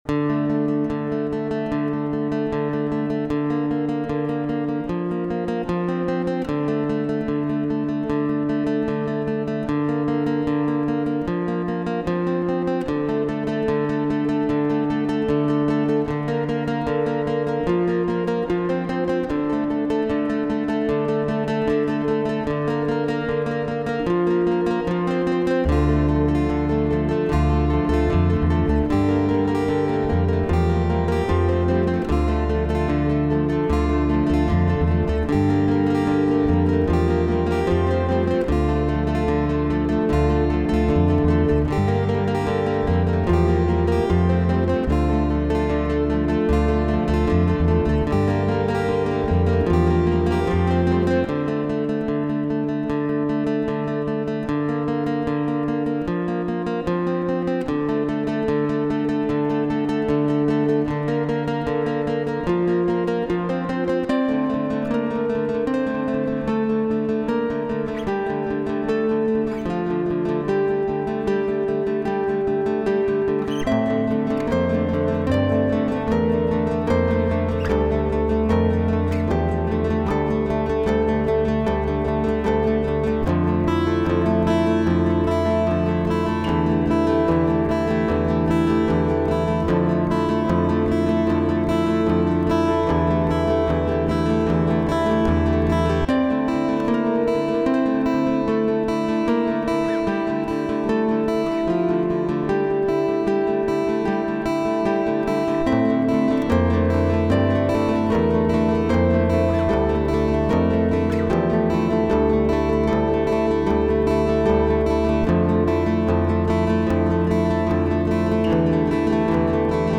Stream and waterfall – with ambient sounds!!!